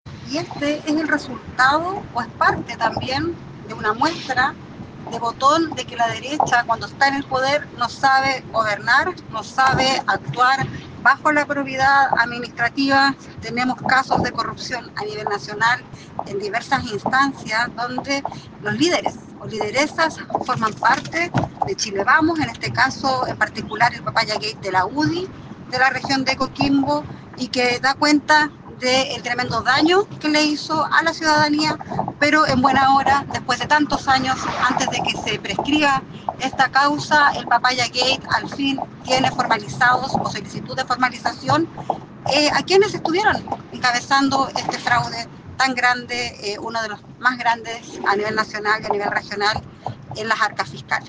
Por su parte, la diputada Nathalie Castillo, quien también ha seguido de cerca el caso y el año pasado se reunió con el fiscal nacional Ángel Valencia para pedir celeridad, señaló: